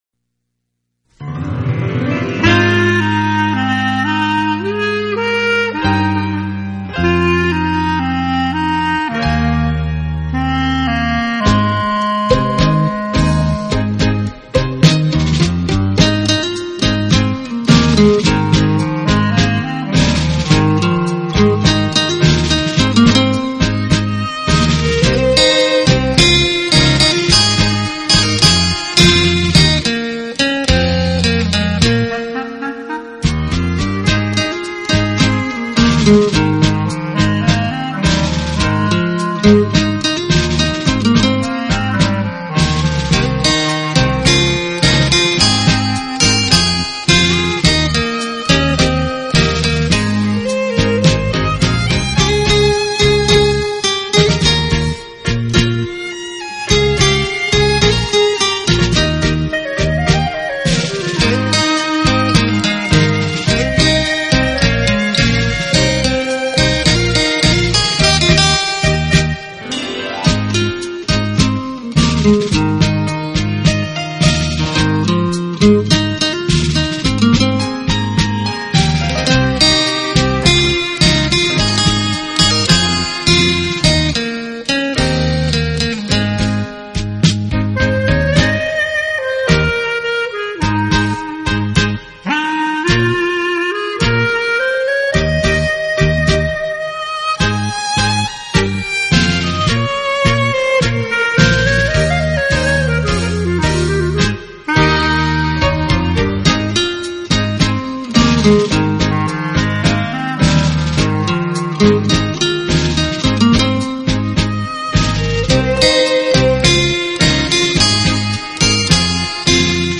专辑音色清脆动人且温馨旖丽，不禁展示了精彩绝伦的空间感，而且带出吉他
音箱共鸣声的无限通透。
用吉他的清脆表现大师音乐的干净、深度和静谧美丽得让人心碎的旋律。